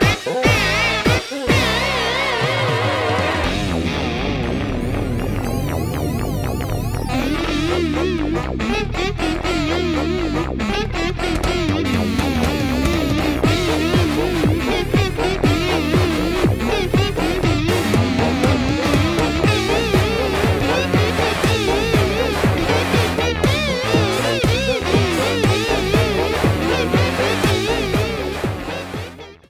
A song
Ripped from the game